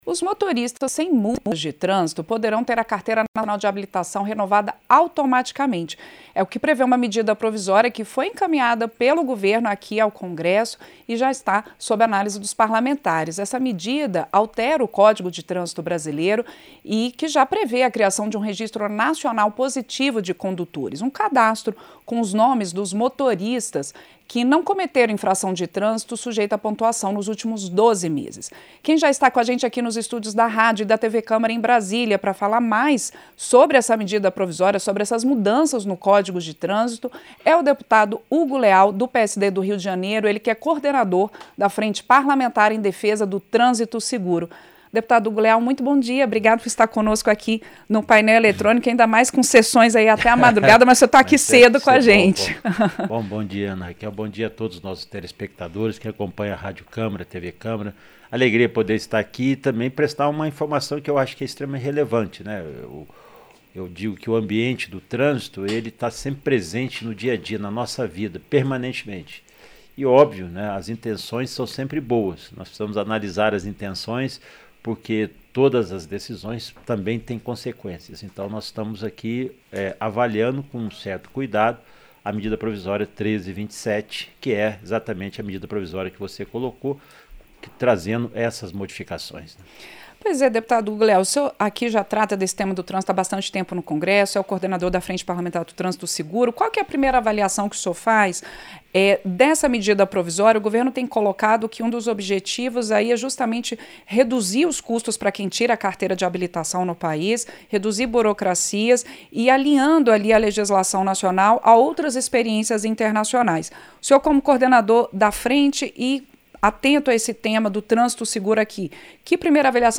Entrevista - Dep. Hugo Leal (PSD-RJ)